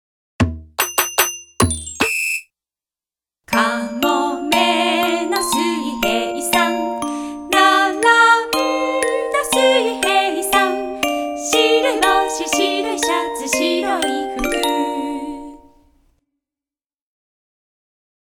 童謡を中心にわらべ歌、唱歌、民謡を収録。